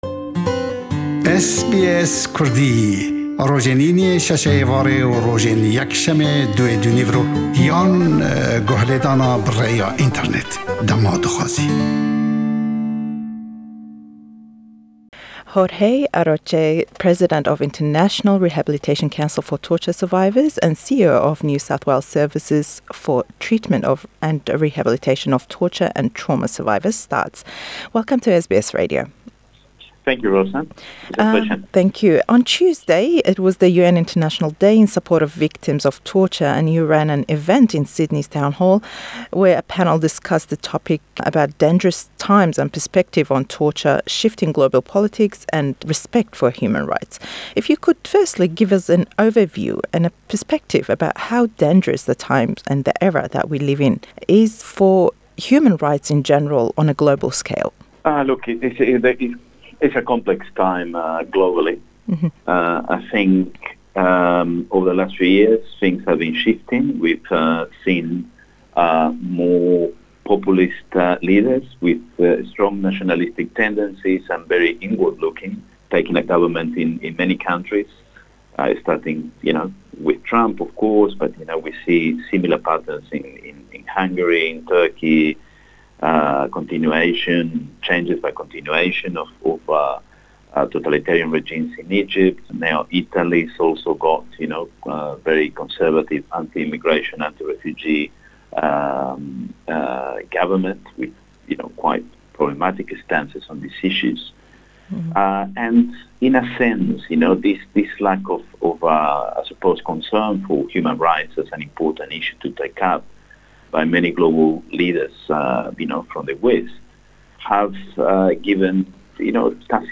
Hevpeyvînî